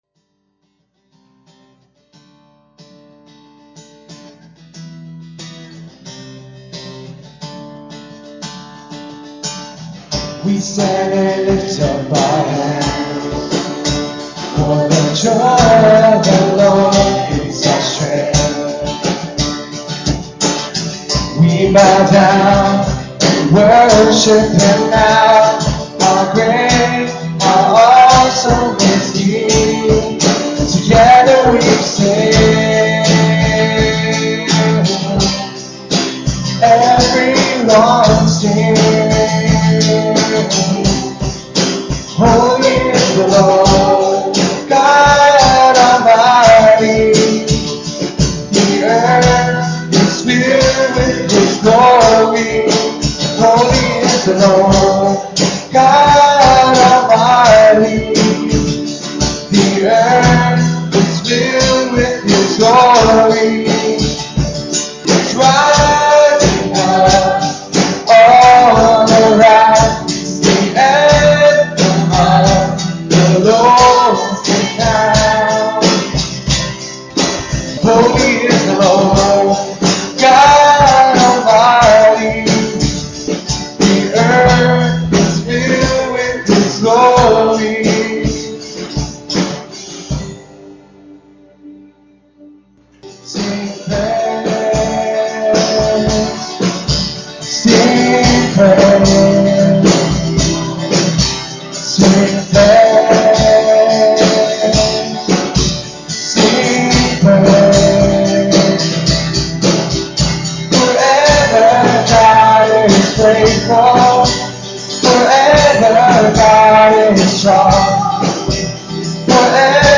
at Ewa Beach Baptist Church